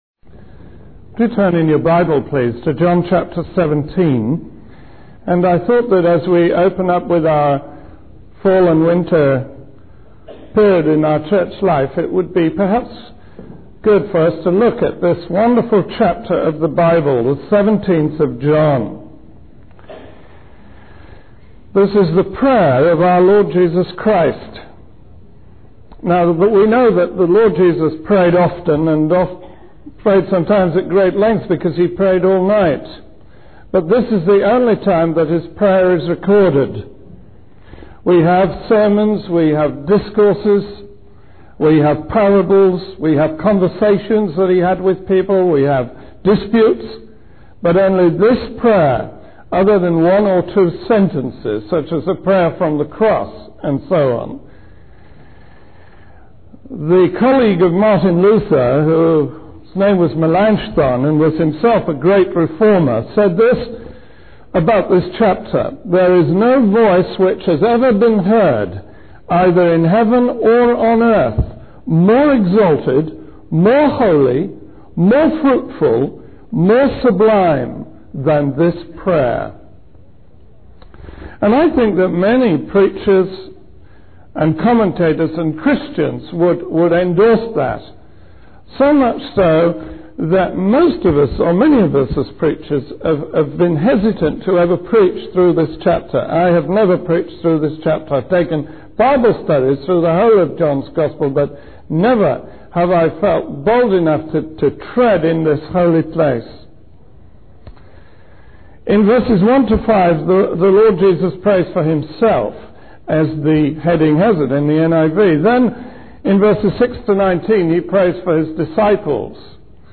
This series of meditations on the prayer of our Lord Jesus Christ recorded in John 17 were originally given at Communion services and are therefore rather shorter than the sermons in other series on this website.